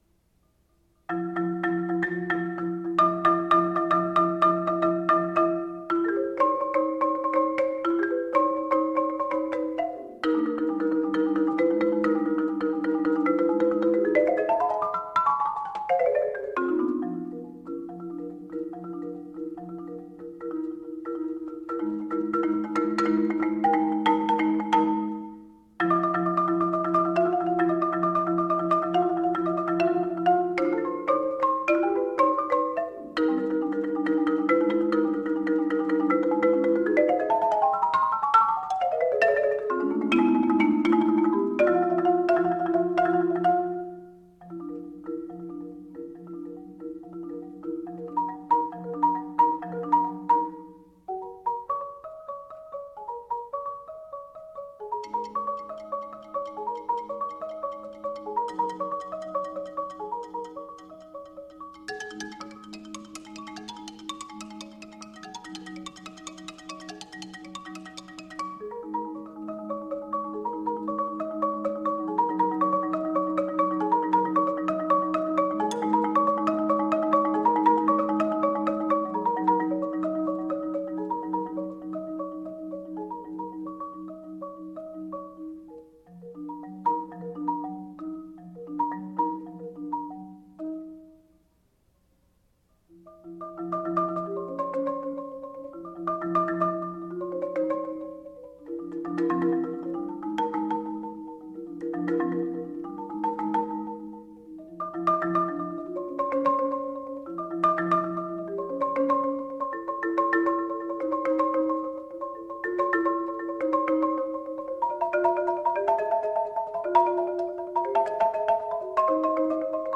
Genre: Marimba (4-mallet)
Marimba (4-octave)